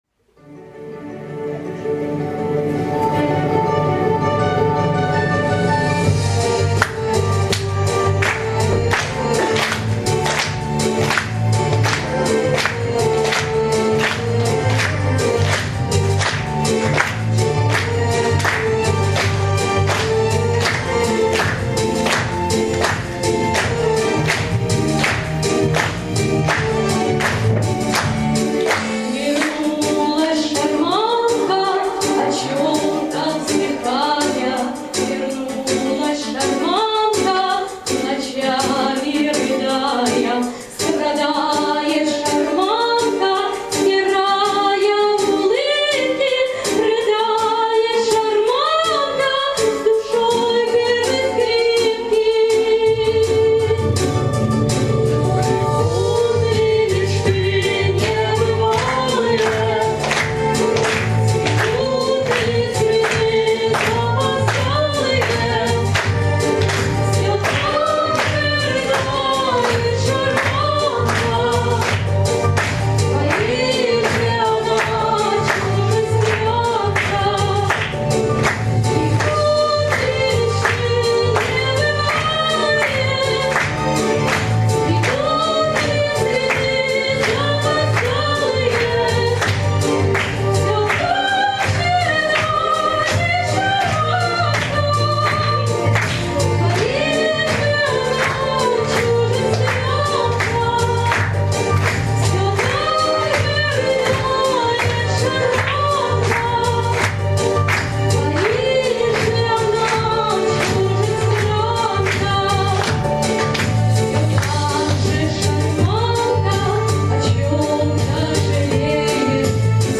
Живое исполнение. Концерт.
Странничество Грусть и радость Ностальгия Душевность